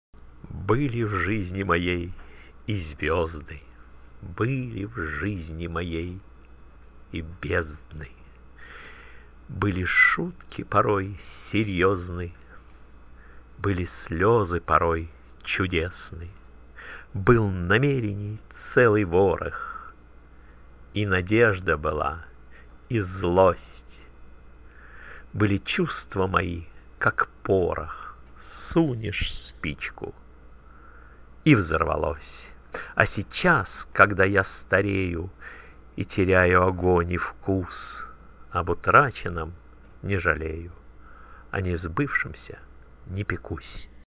Я загружусь и прочту сам.